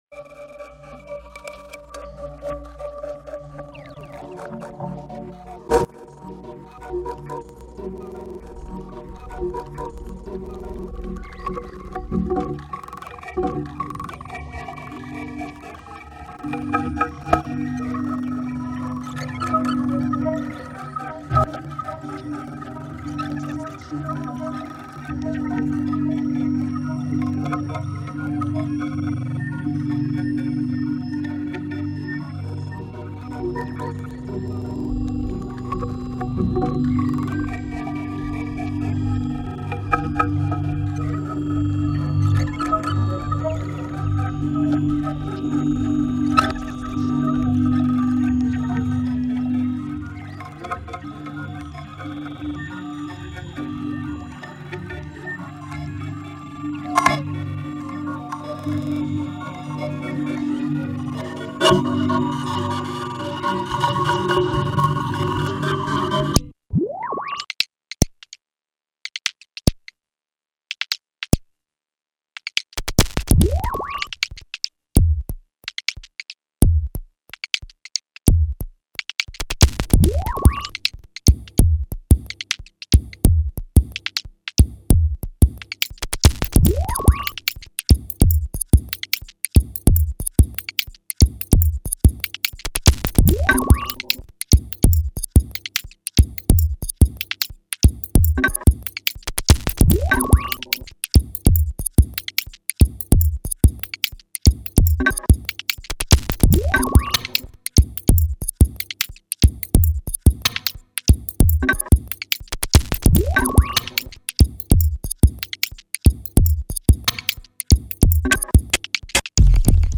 minimalismo
electronic norteña